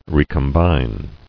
[re·com·bine]